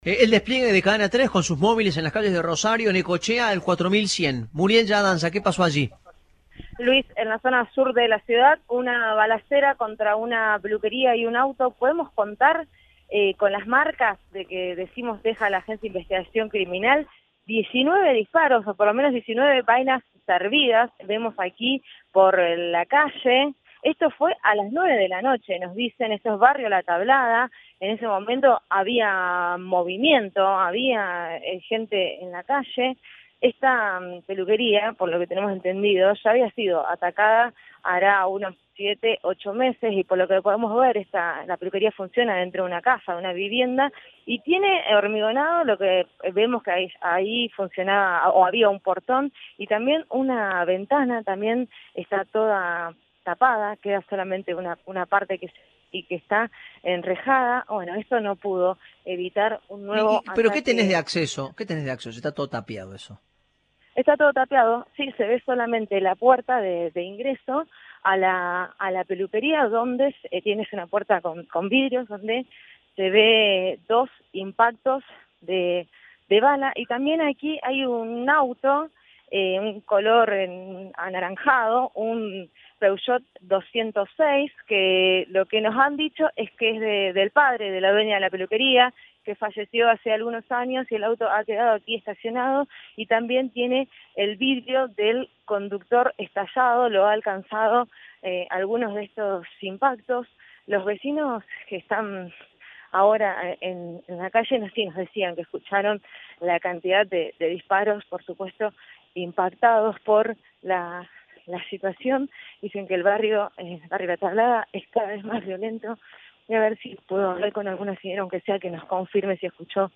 “Escuché los disparos, pero no sé nada, fueron estruendos muy fuertes”, le dijo una vecina al móvil de Cadena 3 Rosario.